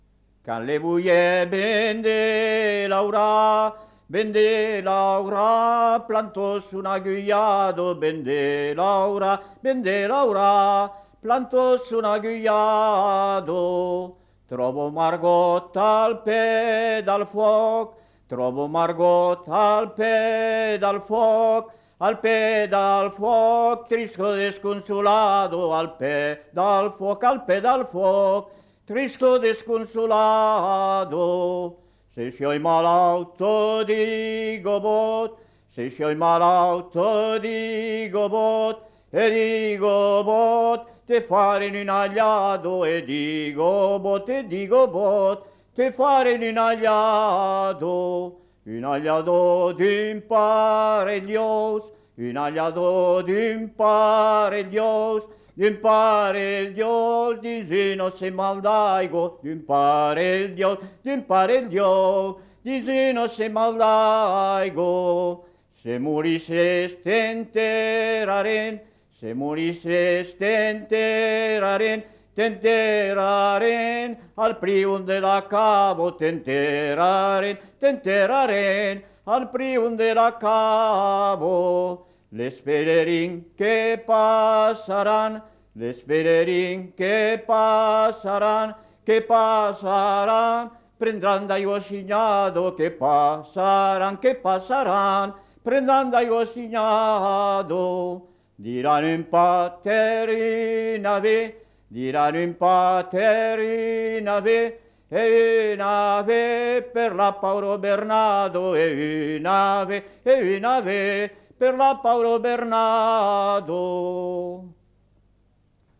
Lieu : La Tourette-Cabardès
Genre : chant
Effectif : 1
Type de voix : voix d'homme
Production du son : chanté